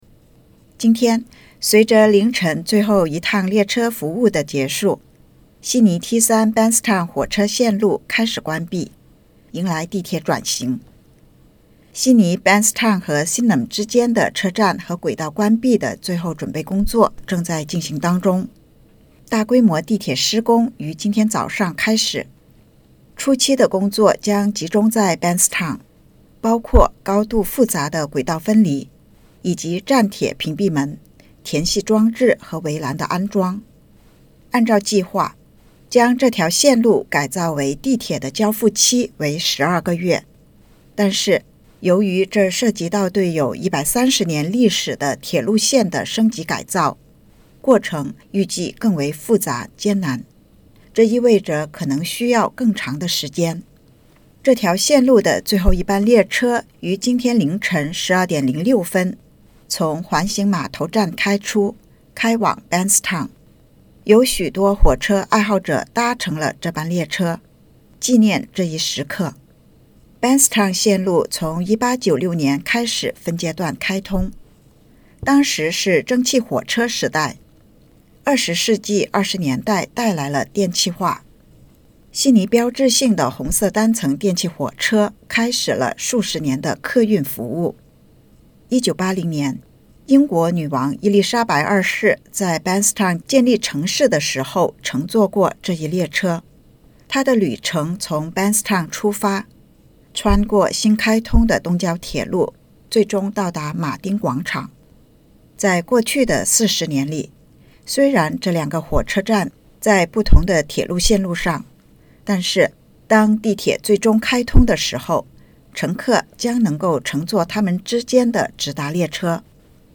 今天（周一），随着凌晨最后一趟列车服务的结束，悉尼T3 Bankstown火车线路开始关闭，迎来地铁转型。（请点击音频播放键收听报道）